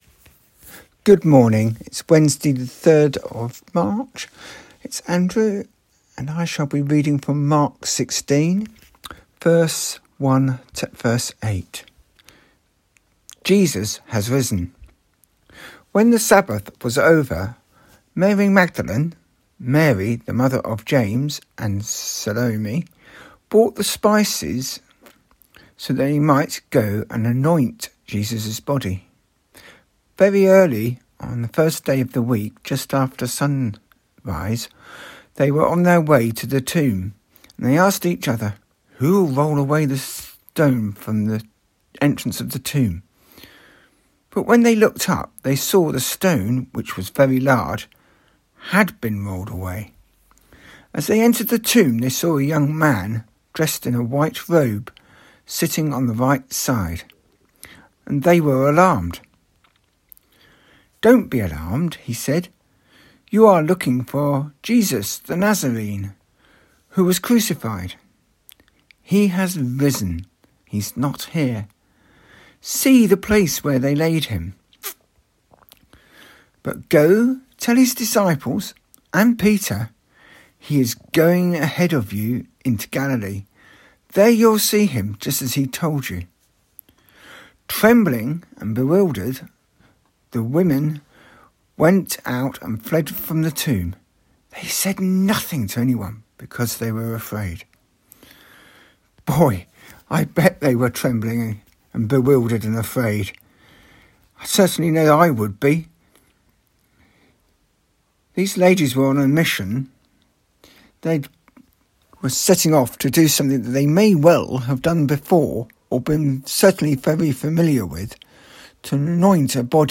Todays’ reading is of Mark 16:1-8